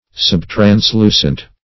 \Sub`trans*lu"cent\